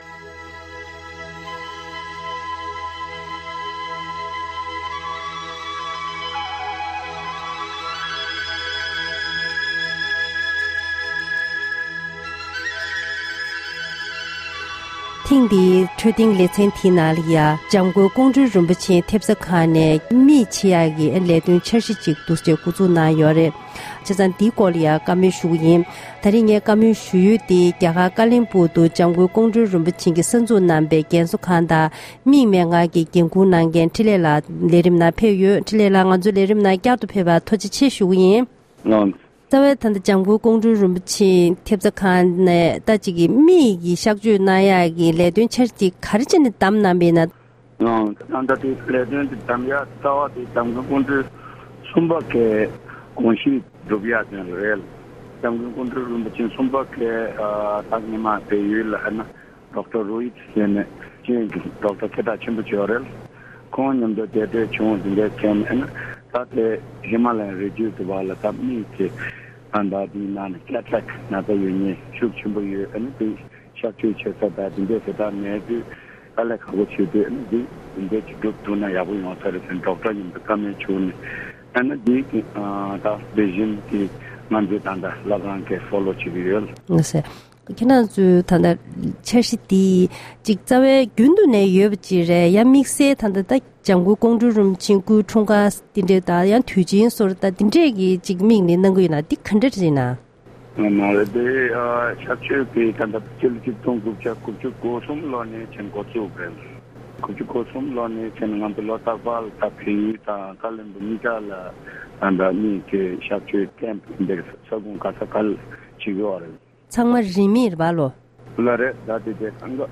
བཀའ་འདྲི་ཞུས་པ་ཞིག